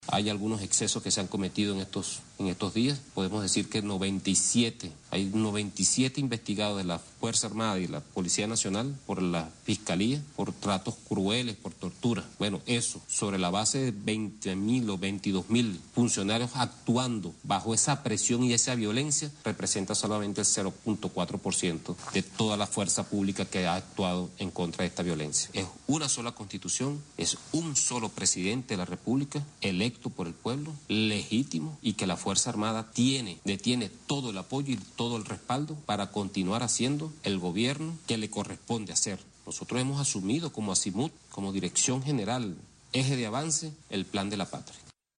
General Vladimir Padrino López informa sobre investigaciones